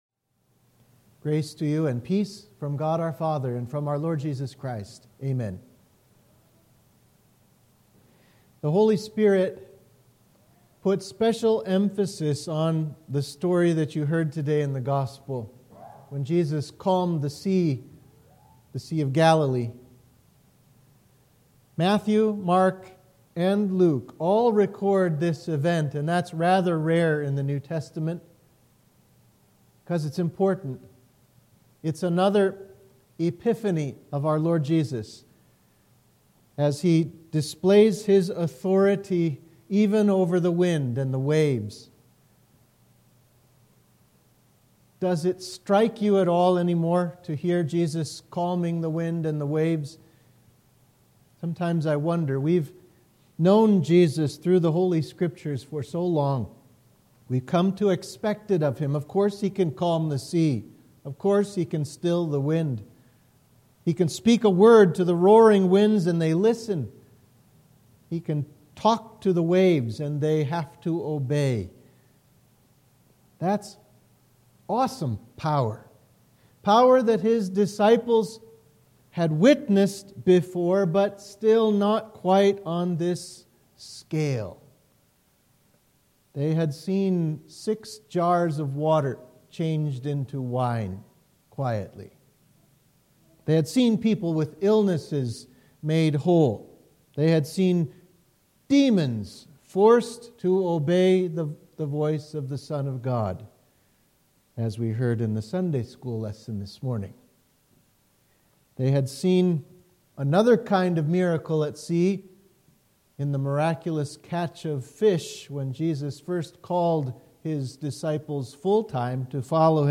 Sermon for the Fourth Sunday after Epiphany